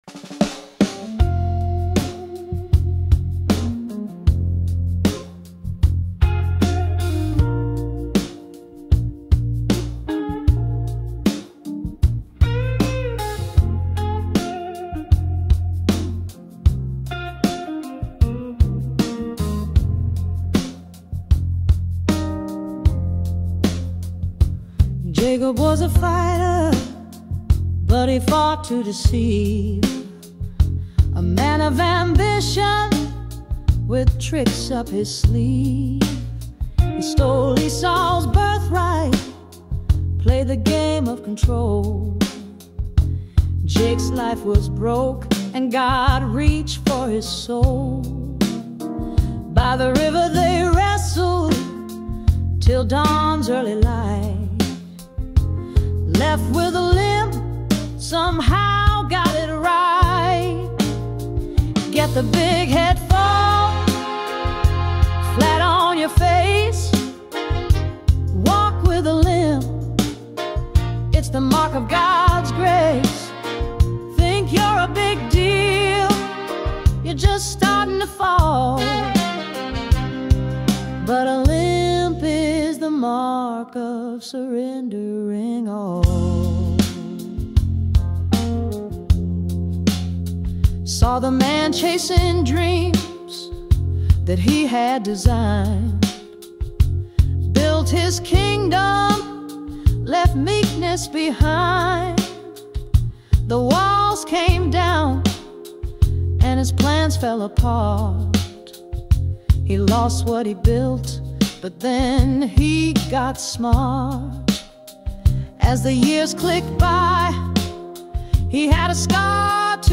IN PRAISE OF LIMPING: A bluesy song I have been working on for a while — inspired by hearing people share their “wrestling stories” and also by living life for 70+ years…